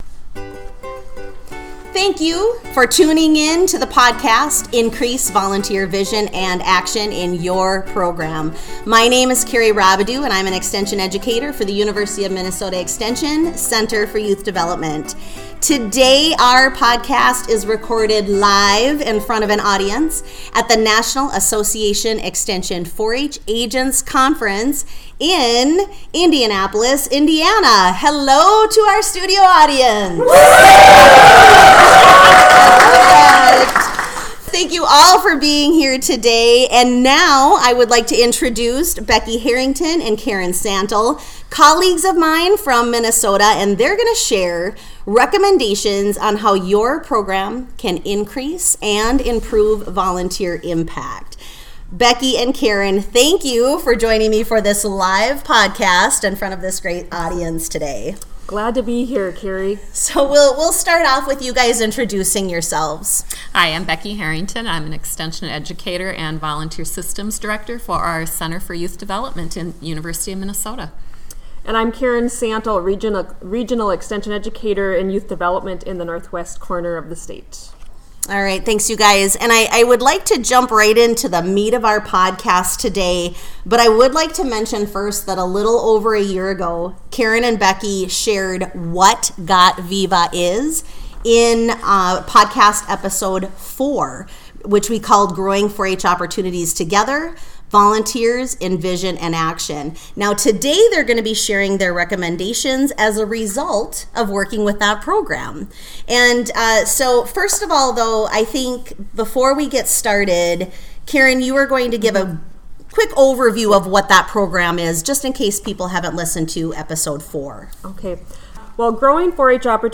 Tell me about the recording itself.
A follow up to episode 4 and recorded live at the NAE4-HA Conference in Indianapolis, Indiana!